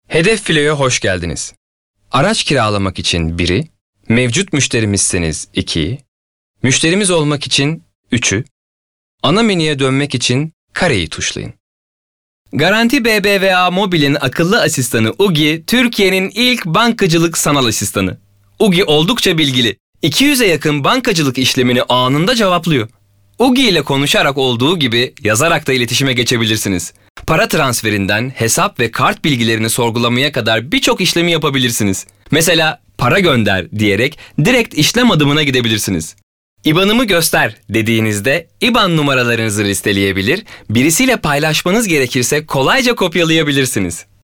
Erkek
KARADENİZ ŞİVE
HOW TO Belgesel, Canlı, Eğlenceli, Güvenilir, Karakter, IVR, Animasyon, Promosyon, Sıcakkanlı, Genç, Dış Ses,